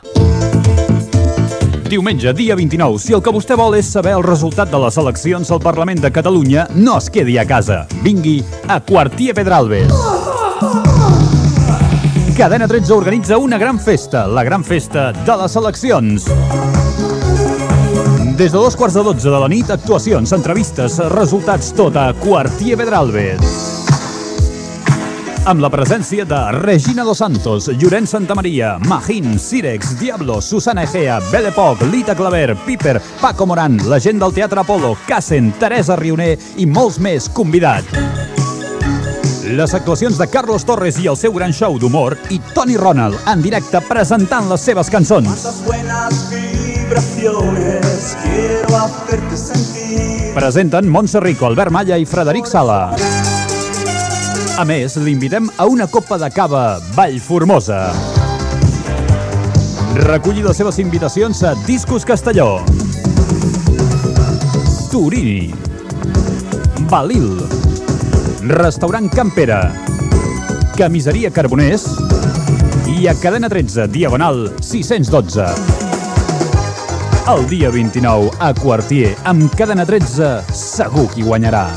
Promoció del programa emès des de Quartier Pedralbes de Barcelona amb motiu de les eleccions autonòmiques de maig de 1988.
Entreteniment